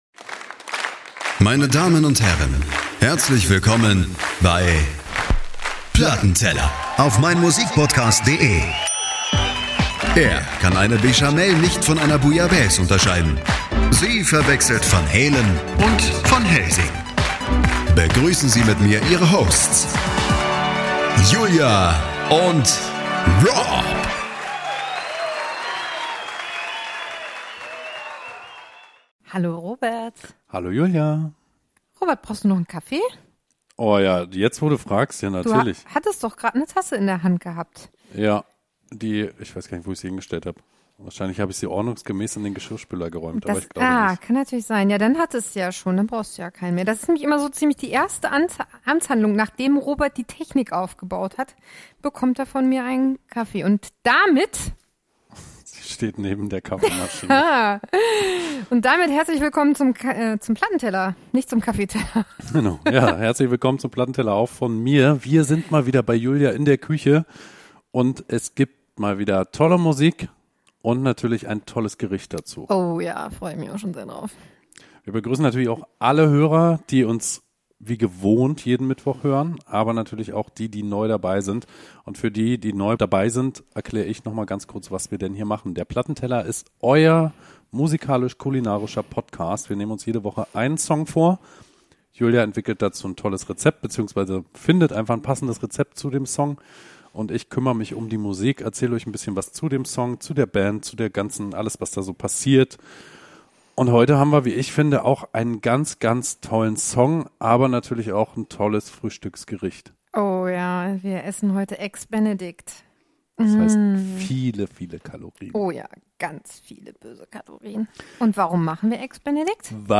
Aber so ist das nun mal in einer „Live“-Show...